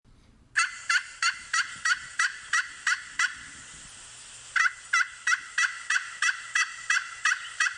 Download Turkey Gobble sound effect for free.
Turkey Gobble